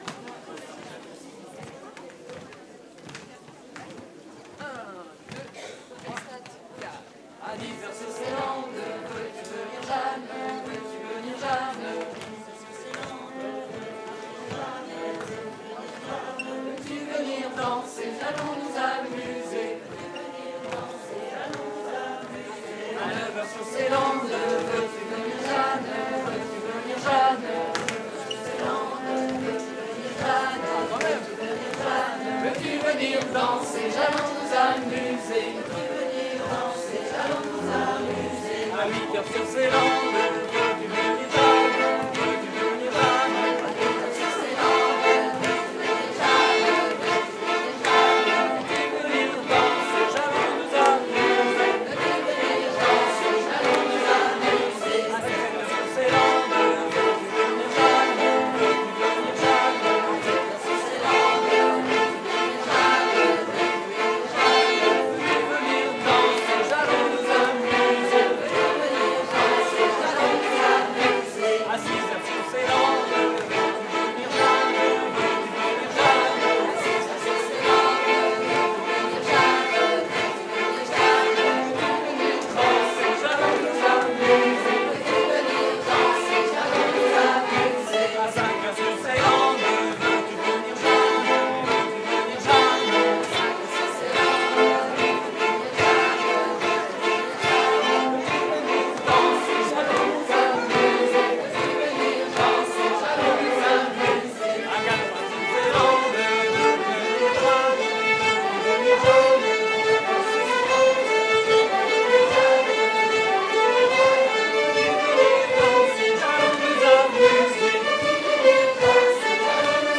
03_rond_sv-violons.mp3